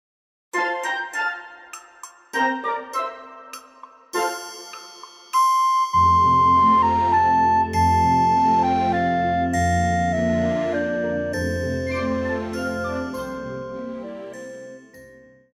高音直笛
樂團
聖誕歌曲,聖歌,教會音樂,古典音樂
獨奏與伴奏
有節拍器